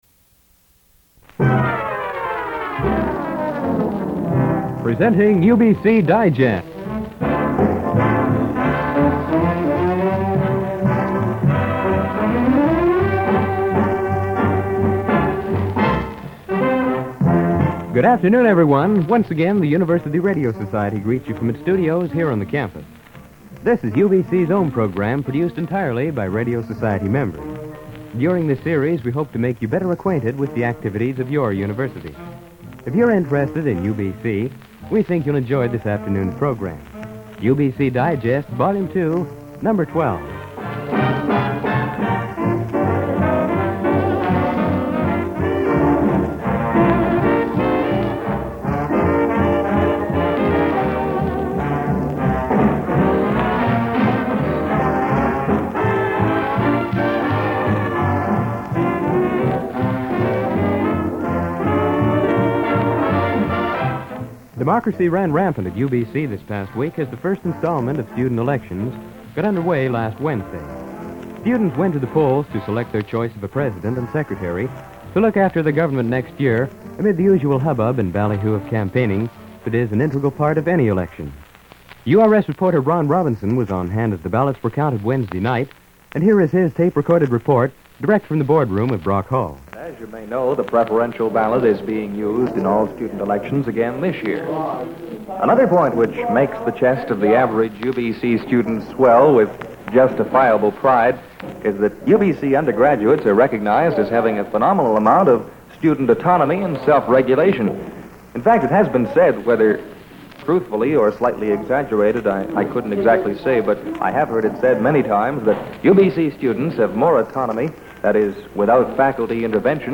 Recording of an episode of the UBC Radio Society's UBC Digest program.